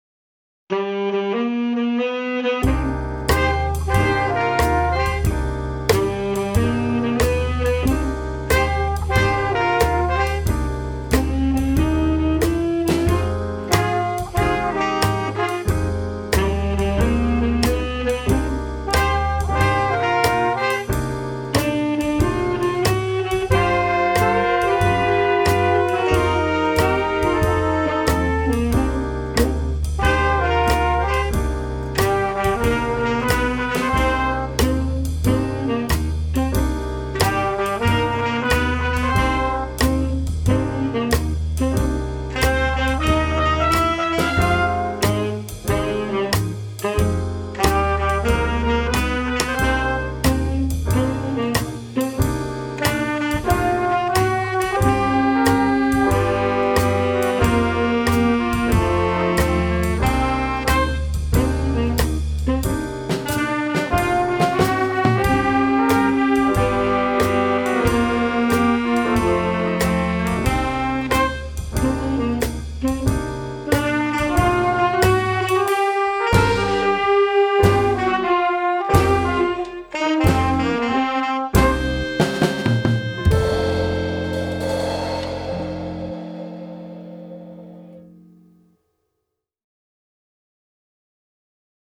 Ein neues Stück von der WRS Bigband ist da! Da alle Musiker*innen des Ensembles zurzeit im Homeoffice verweilen, haben sie ihre Instrumente auch von dort aufgenommen.